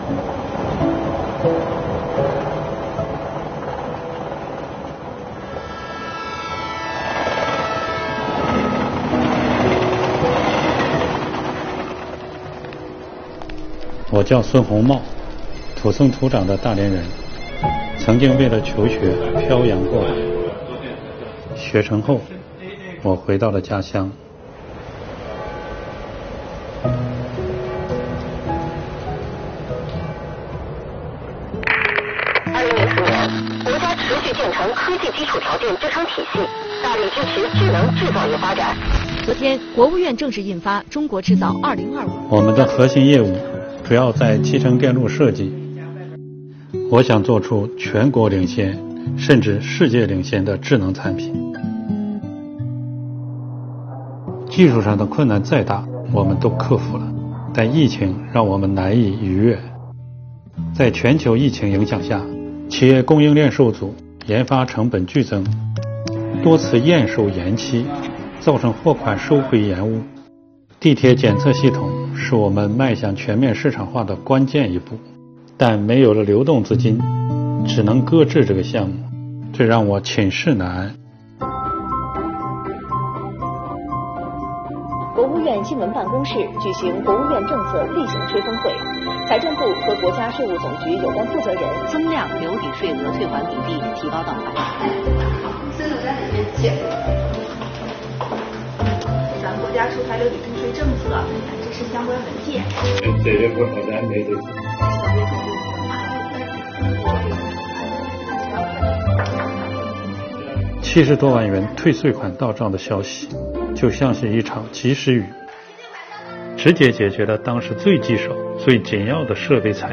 视频拍摄角度全面，细节丰富，镜头剪辑流畅，配乐和故事情节完美结合，相得益彰。